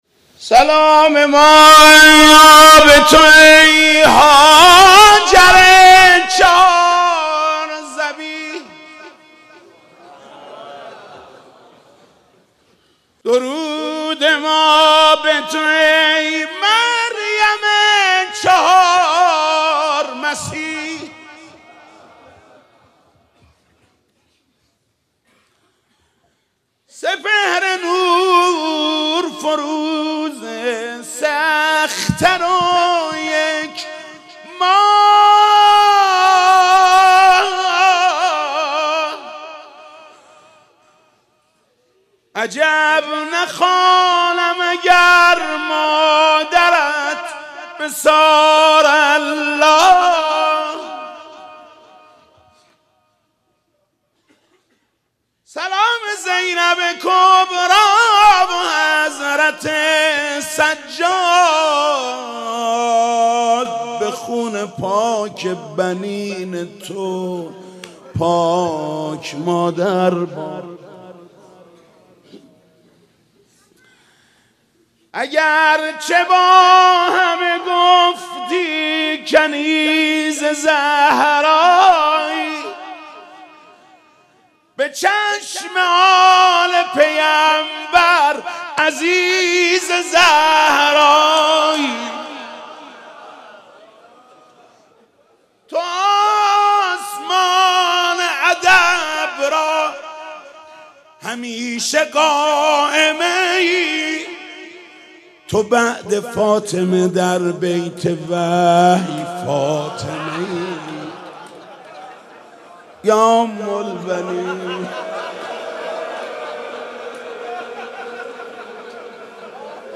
روضه محمود کریمی